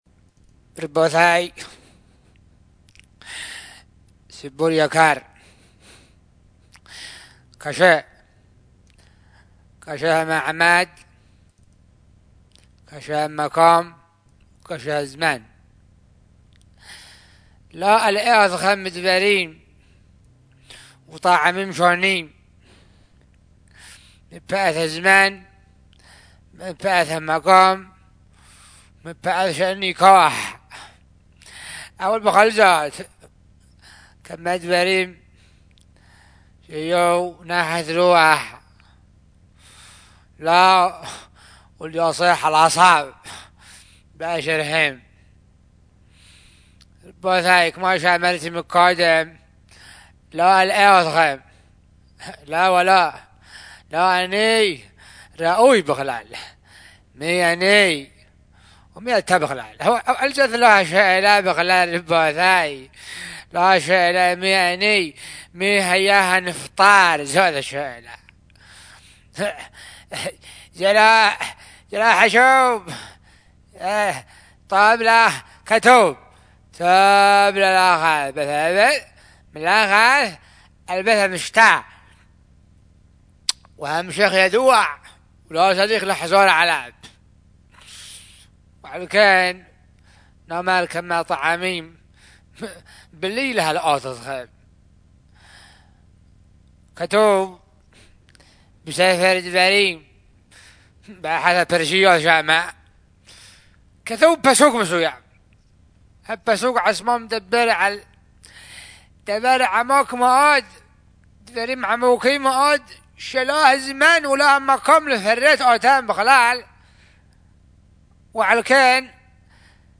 קטע חיקוי לזקן המספיד בסוכת האבלים, ולא מעוניין להלאות את הציבור, קטע אודיו שנמסר לאתר, כולל 4:19 דקות, האזנה עריבה!